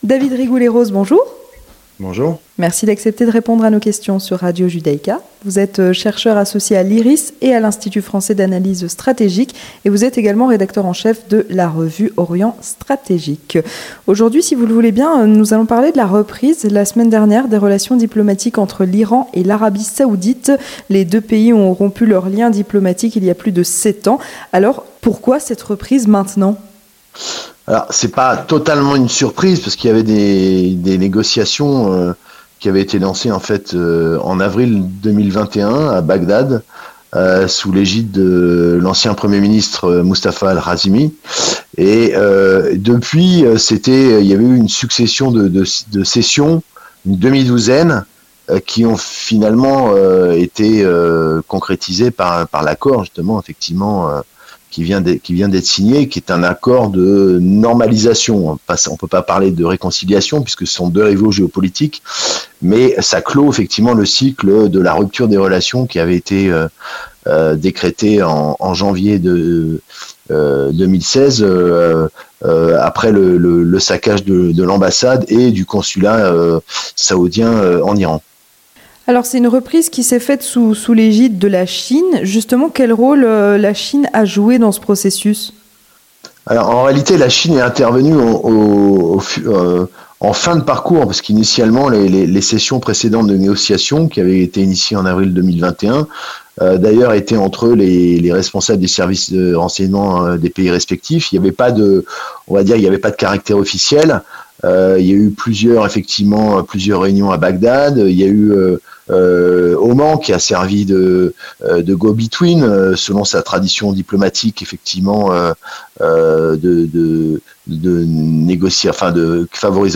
Entretien du 18h - La reprise des relations diplomatiques entre l'Arabie saoudite et l'Iran